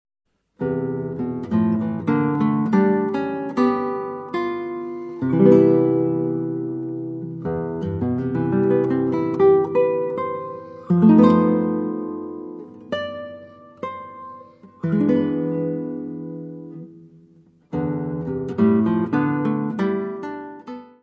guitarra.
Área:  Música Clássica